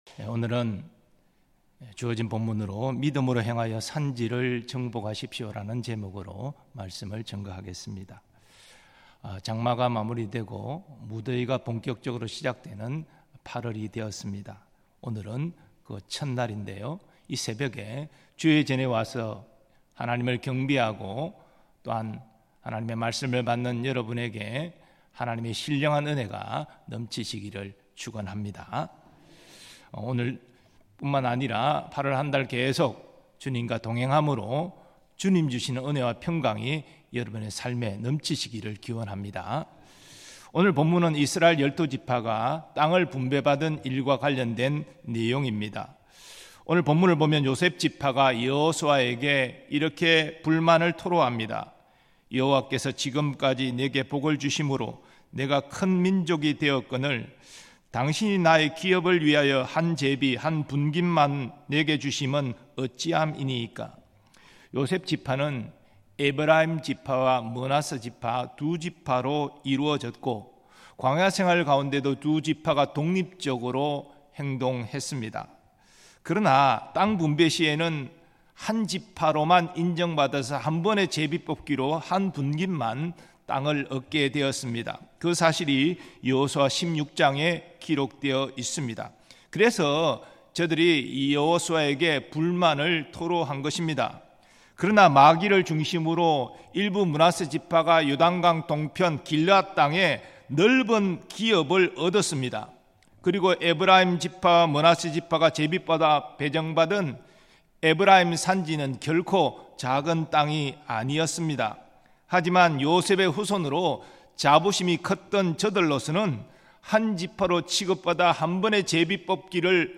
2024년 8월 1일 전교우 새벽기도회
음성설교